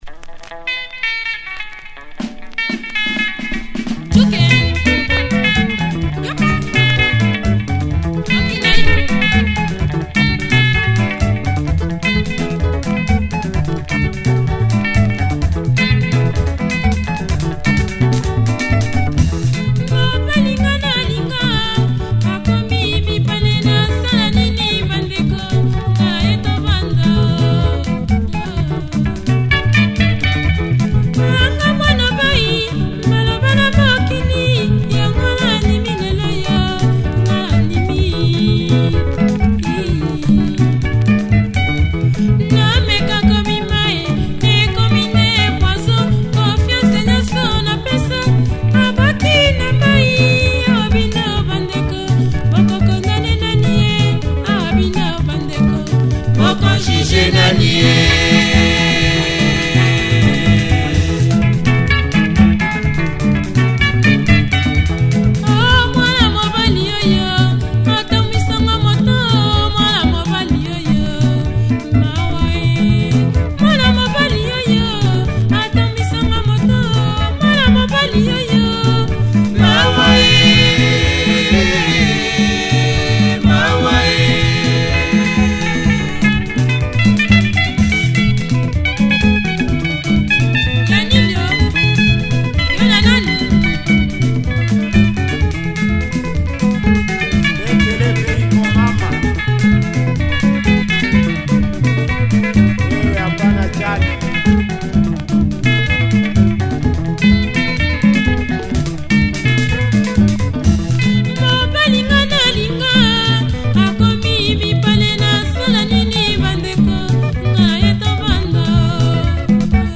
Super Lingala track